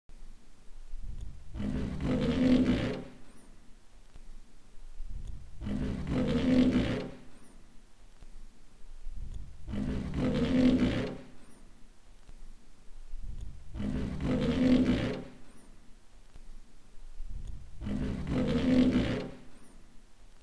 Scheibenwischer-Rubben.mp3